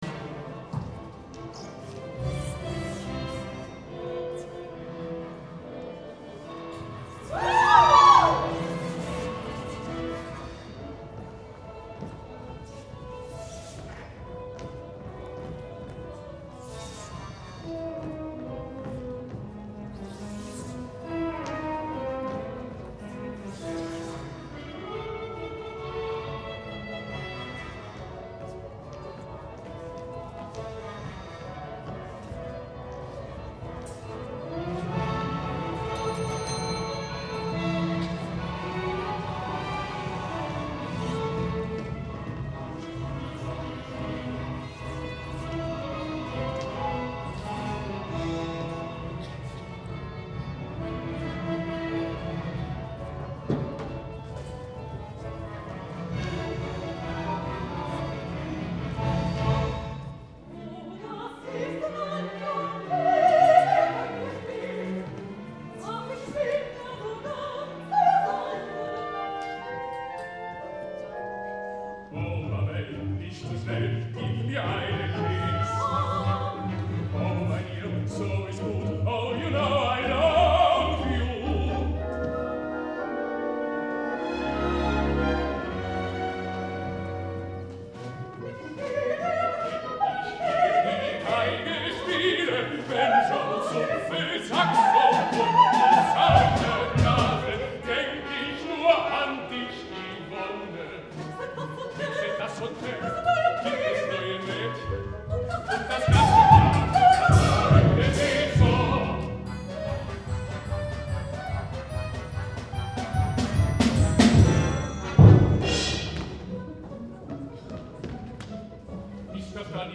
Fragment 2 Een opera van Krenek uit 1927 (Uitvoering Operhaus Wuppertal 2002 )